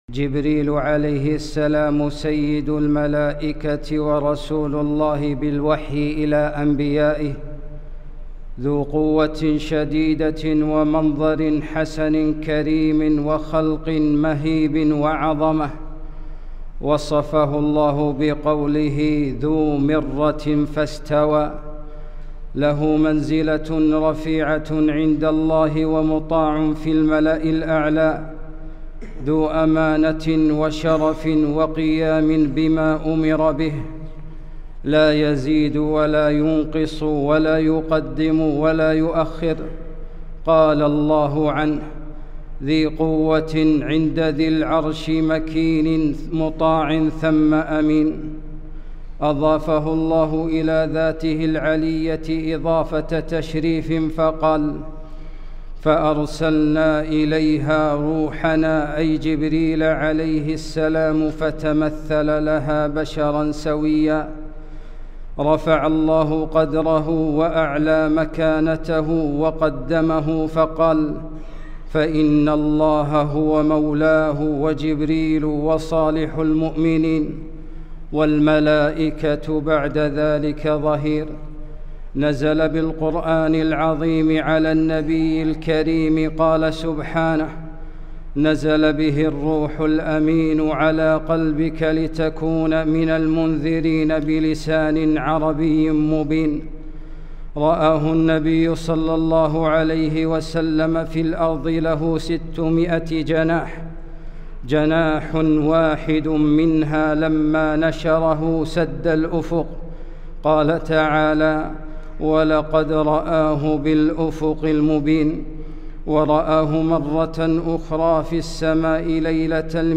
خطبة - سيد الملائكة